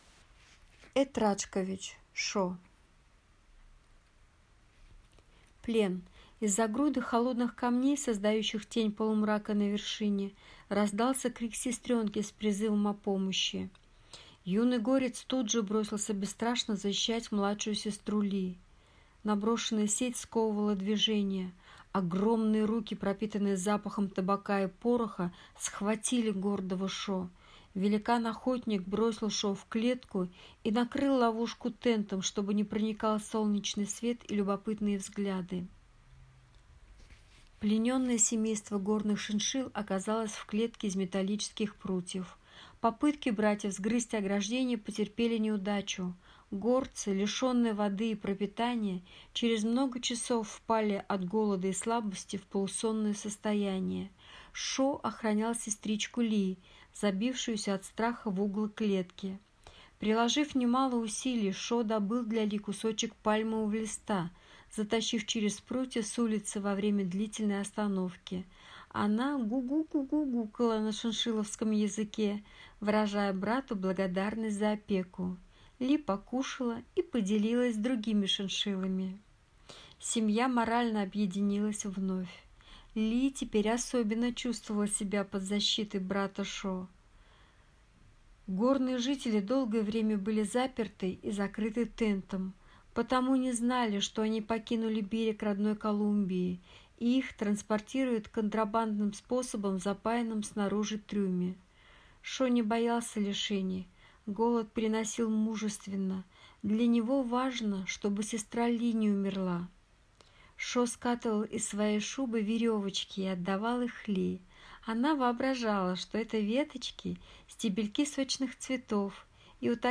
Аудиокнига Шо | Библиотека аудиокниг